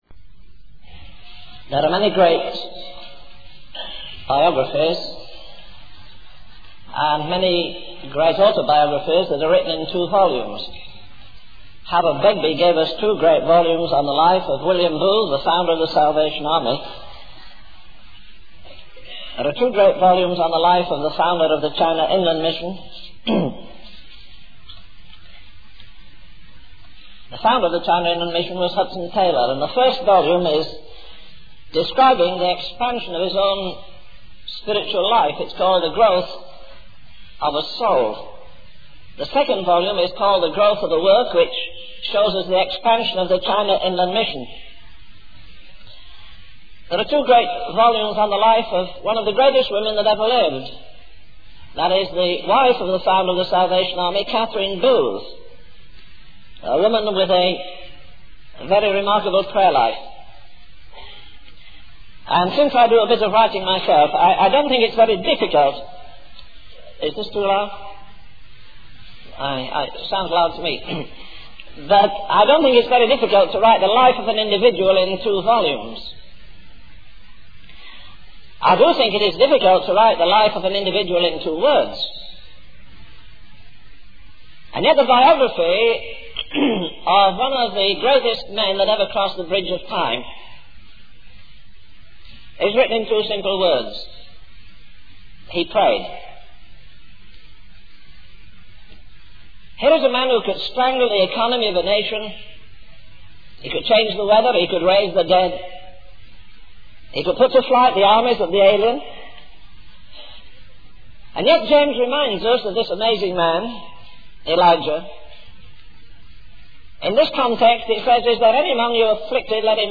In this sermon, the preacher discusses the decline and collapse of the Roman Empire and draws parallels to the present day. He identifies five reasons for the empire's collapse, including the rapid increase of divorce and the undermining of the sanctity of the home, excessive taxation and extravagant spending, and the prioritization of pleasure and the brutalization of sports.